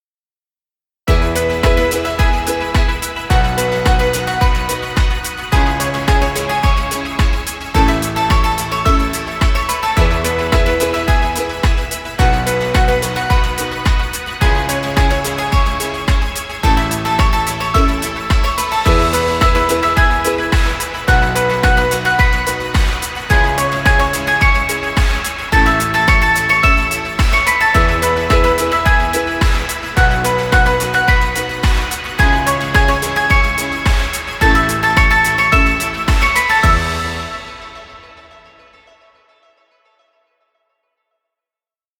Children happy music. Background music Royalty Free.